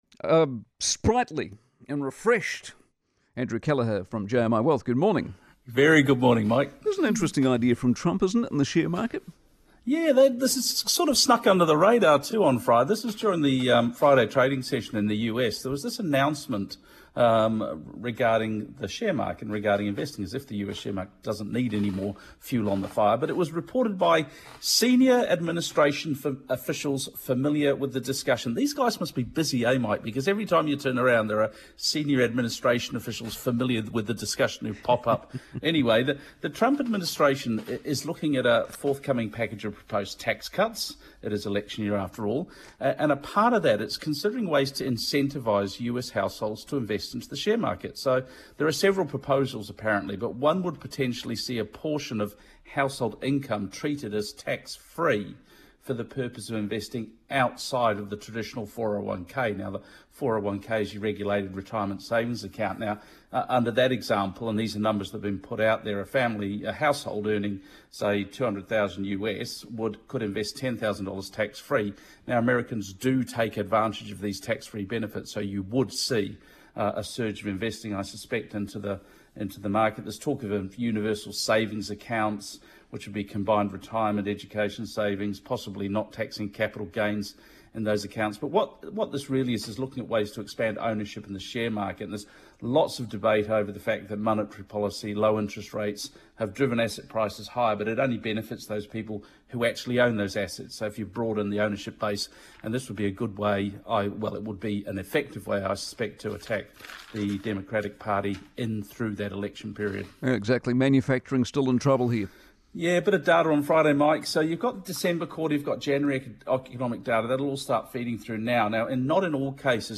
Newstalk ZB Commentary